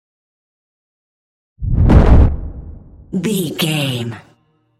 Dramatic deep whoosh to hit trailer
Sound Effects
Atonal
dark
intense
tension
woosh to hit